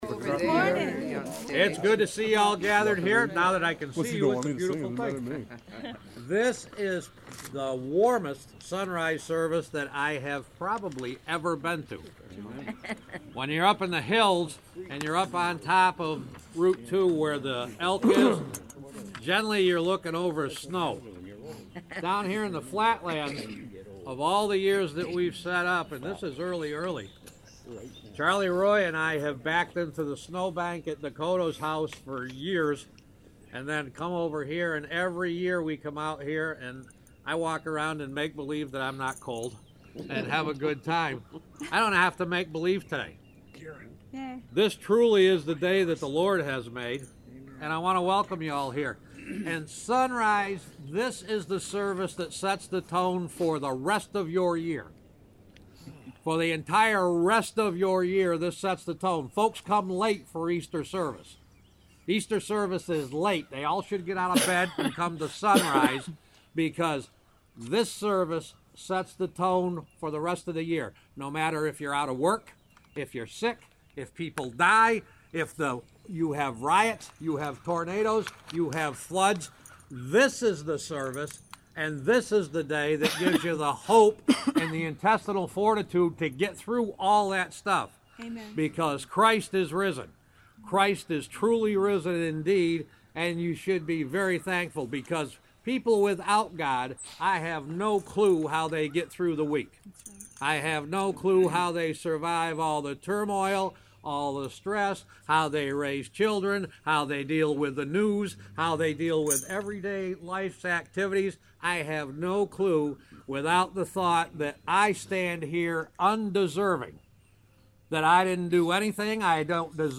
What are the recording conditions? March 27th, 2016 Easter Sonrise Sunday Service Podcast